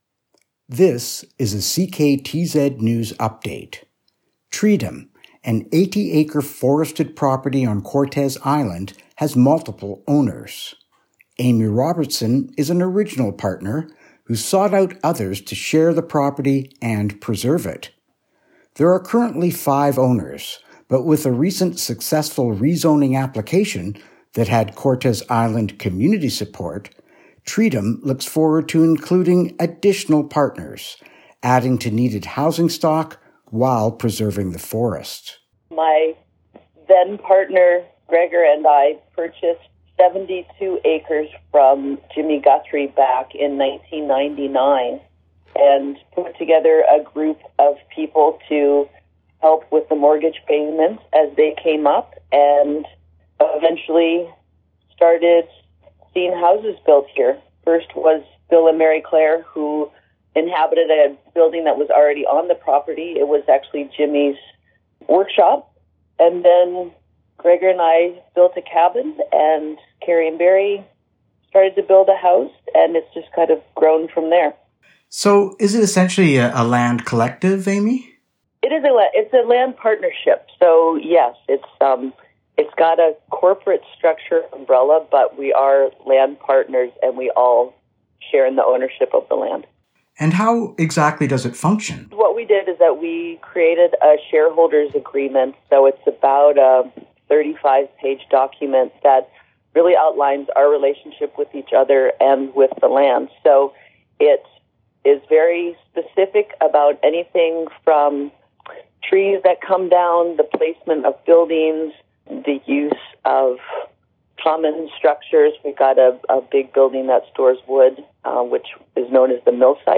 CKTZ-News-Treedom-Dec.2021.mp3